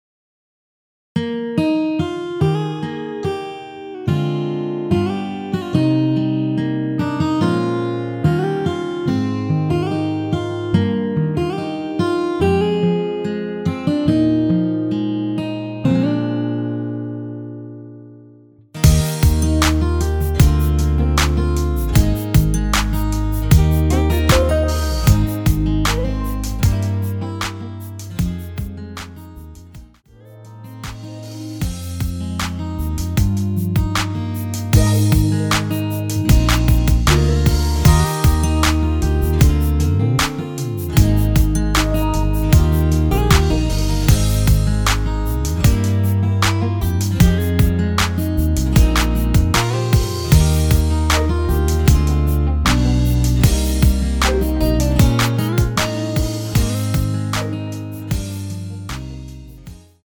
원키(1절+후렴) MR입니다.
Eb
앞부분30초, 뒷부분30초씩 편집해서 올려 드리고 있습니다.